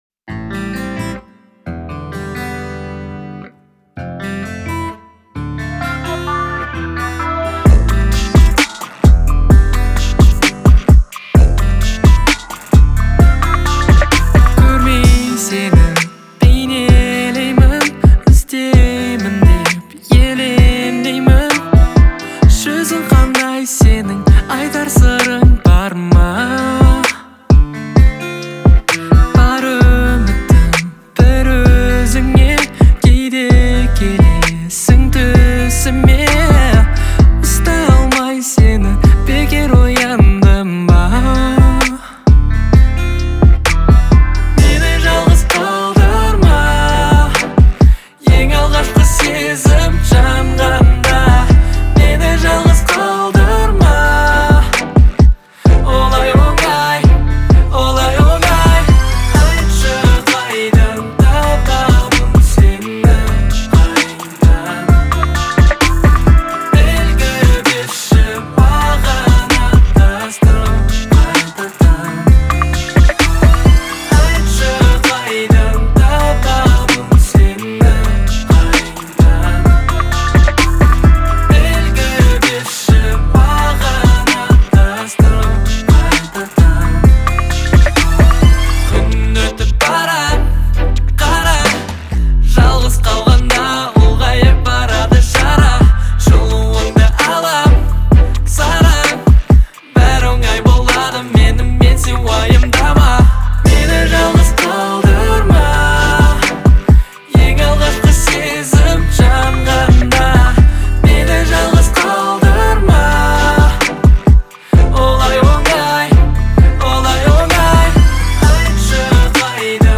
это завораживающий трек в жанре инди-поп
нежный вокал с атмосферными инструментами